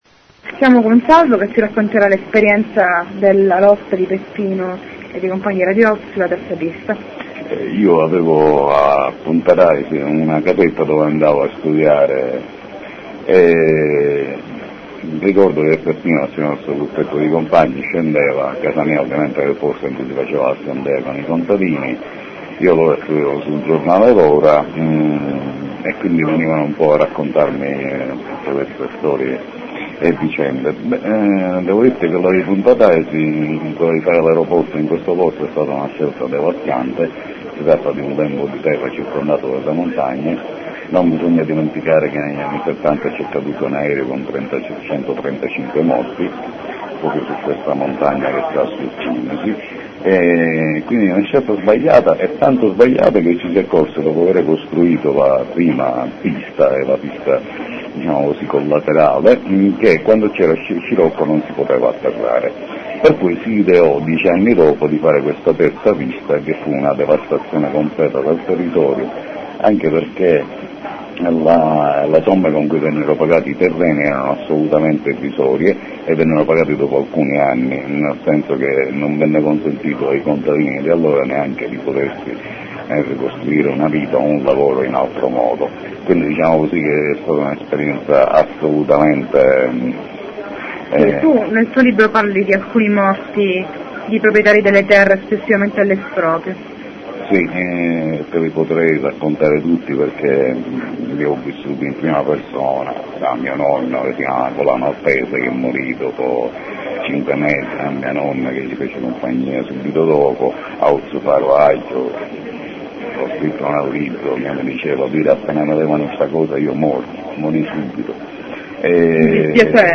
I ricordi di un compagno di lotta di Peppino Impastato: l'opposizione popolare alla costruzione della famosa "terzapista" dell'aereoporto di Palermo.